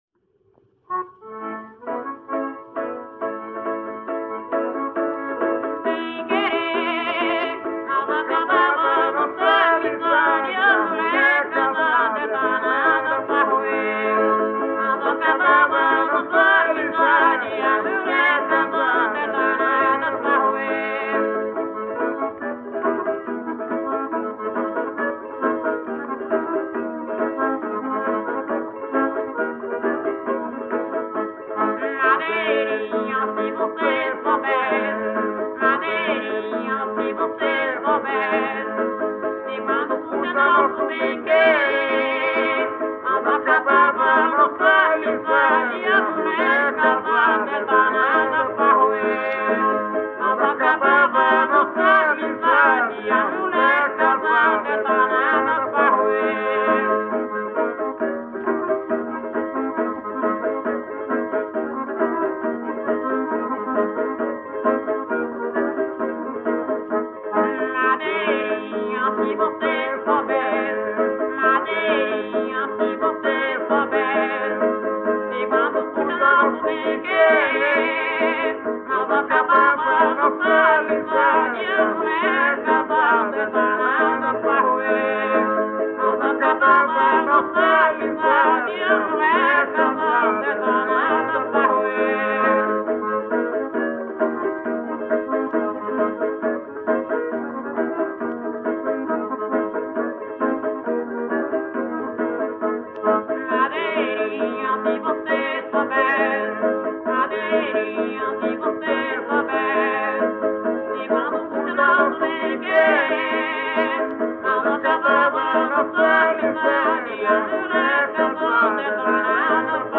Marcha – “”Mulher casada”” - Acervos - Centro Cultural São Paulo